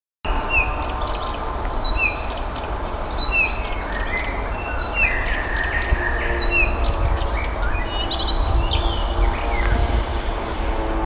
Blyth’s Reed Warbler, Acrocephalus dumetorum
Administratīvā teritorijaRīga
StatusSinging male in breeding season